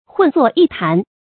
混作一谈 hùn zuò yī tán
混作一谈发音